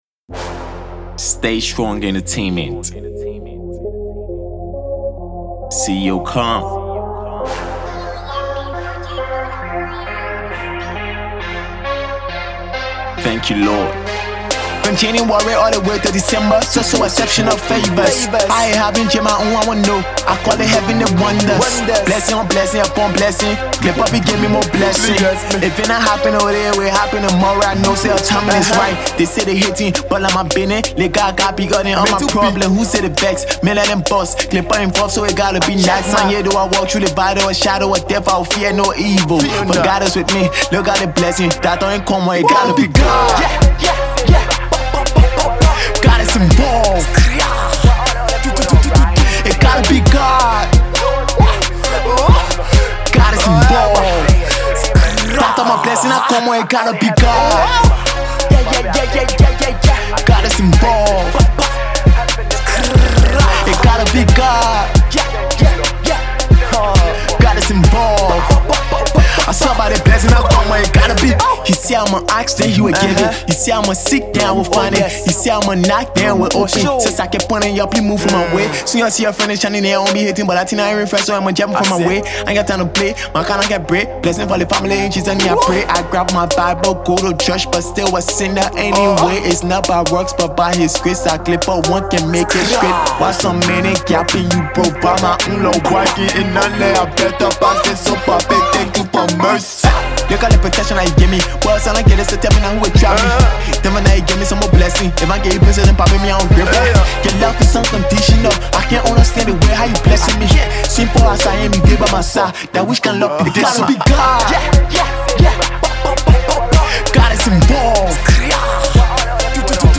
/ Gosple, Colloquial, Hip-Hop / By